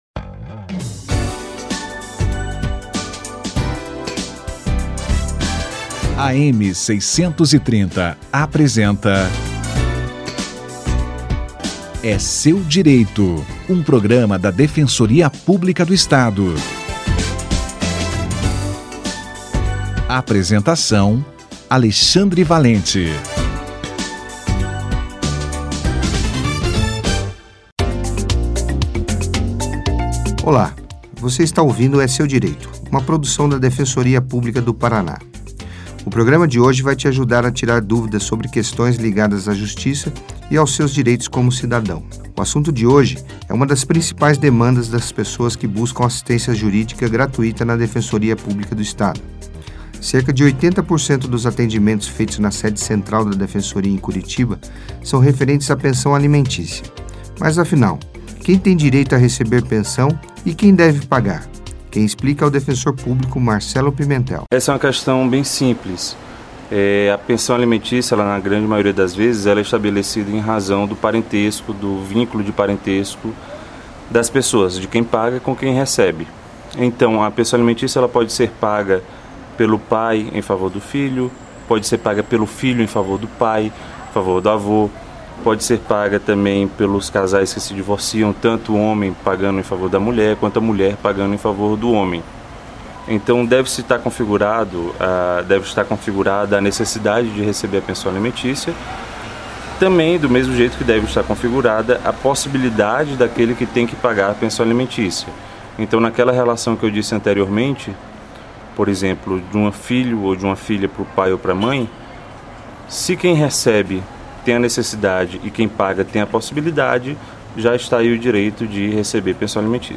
Direitos e Deveres na Pensão Alimentícia - Entrevista